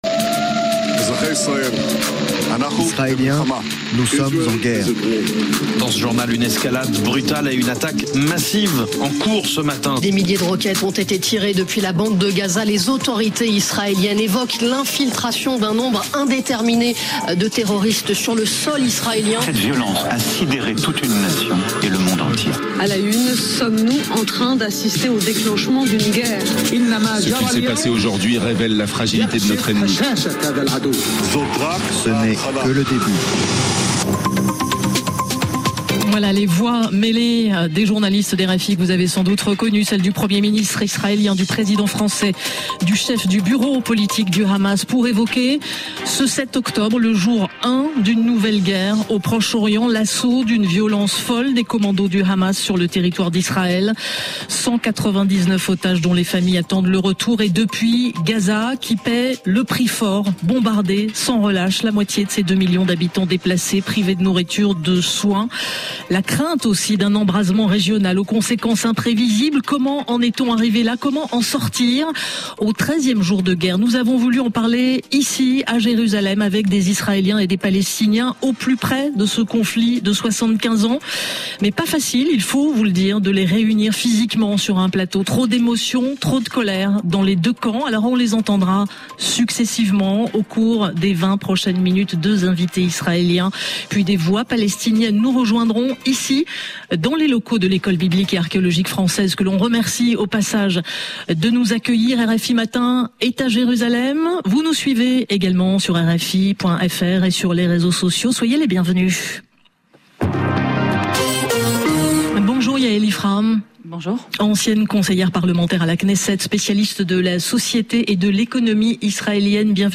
RFI est en édition spéciale en direct de Paris et de Jérusalem ce jeudi matin pour évoquer le conflit en cours entre le Hamas et Israël. L’antenne donne la parole aux témoins, aux journalistes, aux politiques, aux historiensisraéliens et palestiniens pour analyser la situation et ses enjeux y compris internationaux, et tenter de dresser des pistes pour l’avenir.